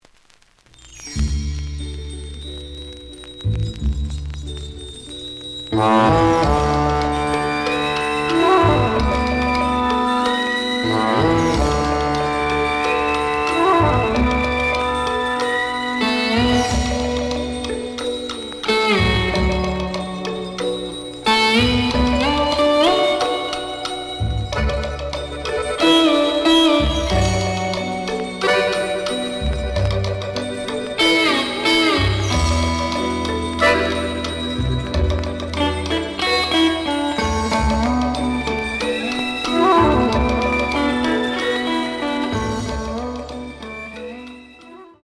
The audio sample is recorded from the actual item.
●Format: 7 inch
●Genre: Rock / Pop
Slight damage on both side labels. B side plays good.)